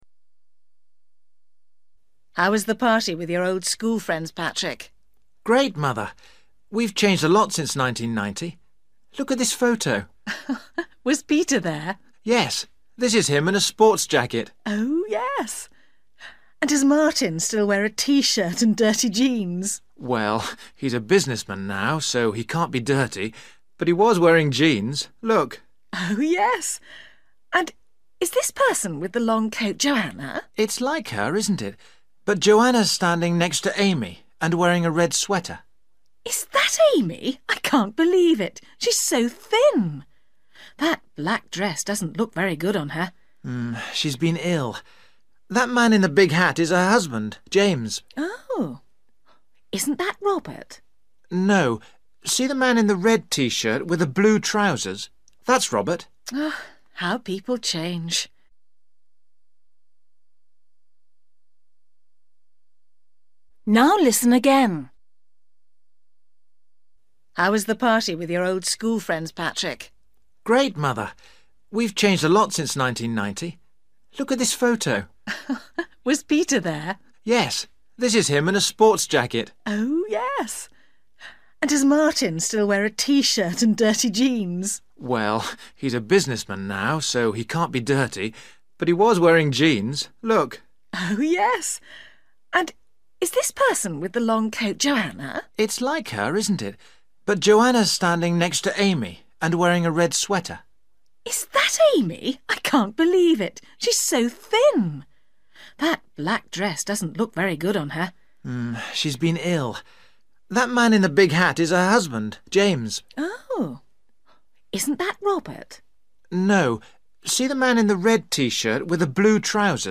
Bài tập luyện nghe tiếng Anh trình độ sơ trung cấp – Nghe cuộc trò chuyện và chọn câu trả lời đúng phần 55
Listening: What is each person wearing?